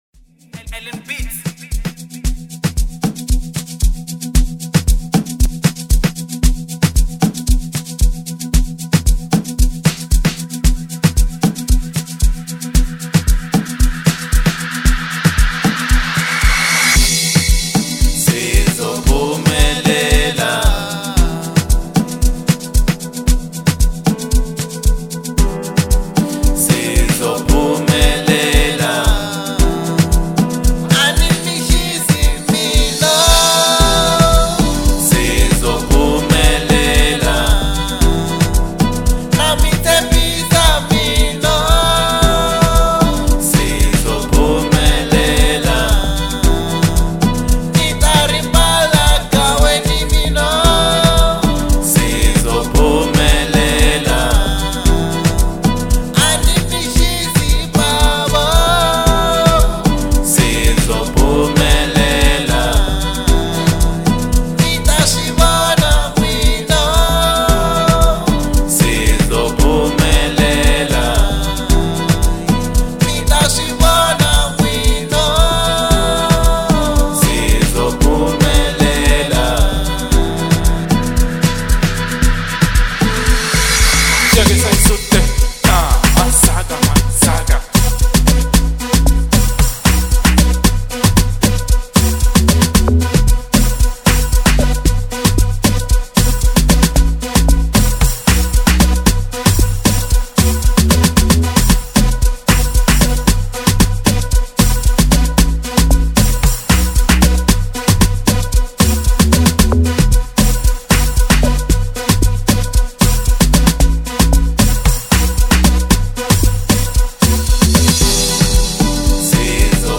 04:37 Genre : Amapiano Size